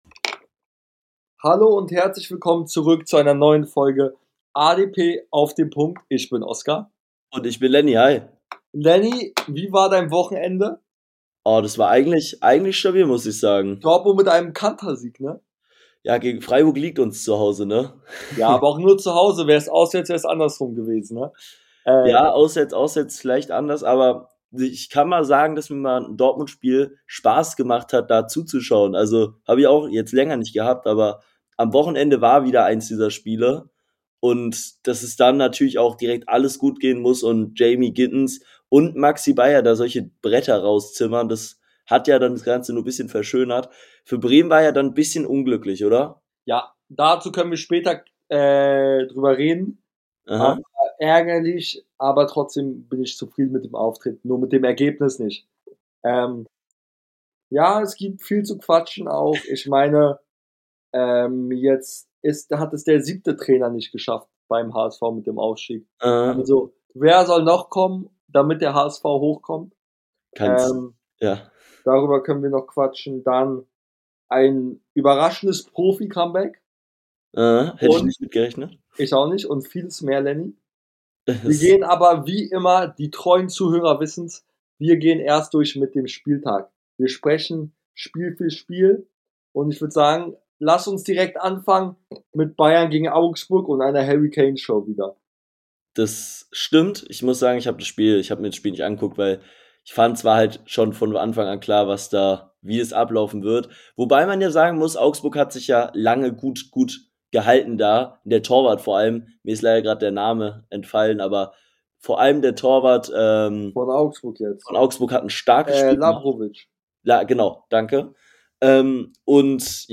In der heutigen Folge reden die beiden Hosts über die Trainer Entlassung beim HSV, Roses Krise bei Leipzig und vieles mehr